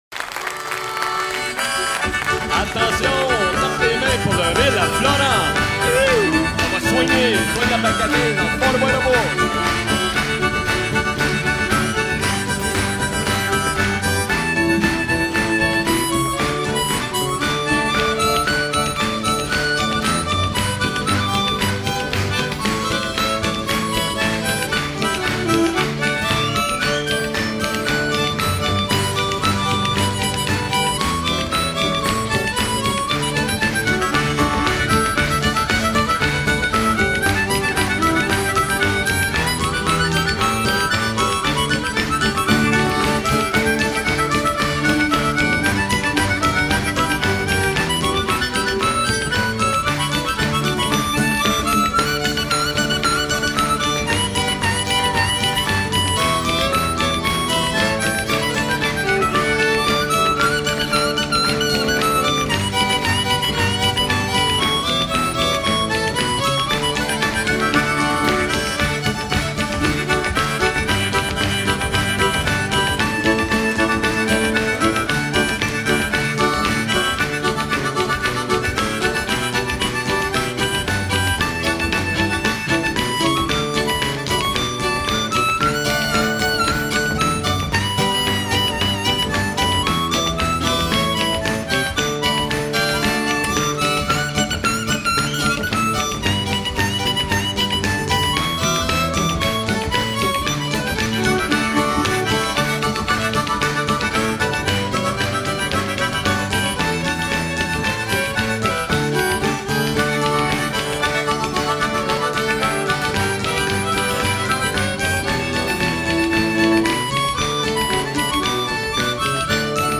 reel populaire de pêcheurs des côtes de Gaspésie et d'Acadie
danse : reel